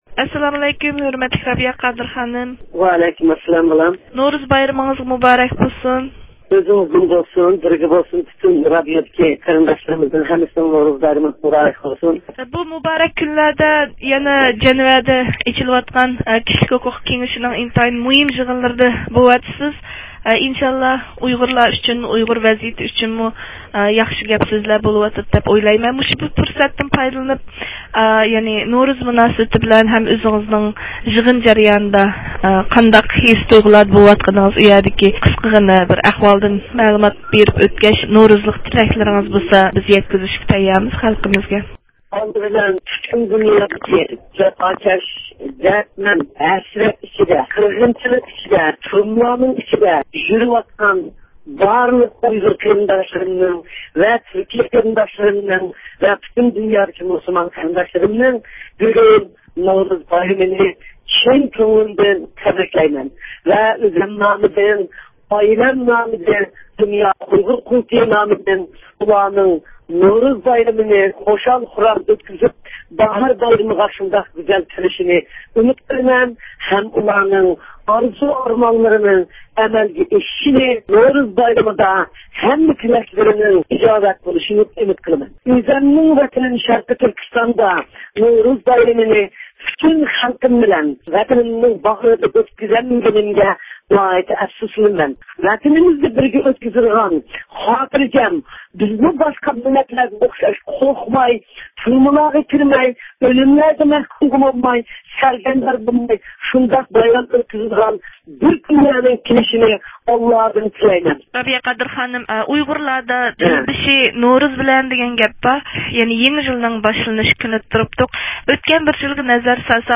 بۇ قۇتلۇق بايرام كۈنلىرىدە ئەل غېمى ئۈچۈن ب د ت كىشىلىك ھوقۇق كېڭىشىنىڭ 25-نۆۋەتلىك ئىنسان ھەقلىرى ئومۇمىي يىغىنىغا قاتنىشىۋاتقان ئۇيغۇر مىللىي ھەرىكىتى رەھبىرى رابىيە قادىر خانىم، يىغىن ئارىلىقىدا مۇخبىرىمىزنىڭ زىيارىتىنى قوبۇل قىلىپ، ئۇيغۇرلارنى نورۇز ئىلھامى بىلەن ئەركىنلىك، بەخت نىشانلىرىغا قاراپ قولنى قولغا تۇتۇشۇپ، ئۆملۈك تۇغىنى ئېگىز كۆتۈرۈپ غەيرەت ۋە ئىشەنچ بىلەن ئەتىگە بېقىشقا چاقىردى ۋە ئۇيغۇر خەلقىگە جۈملىدىن پۈتۈن نورۇز ئەھلىگە بەخت-تەلەي ۋە ئاسايىشلىق تىلىدى.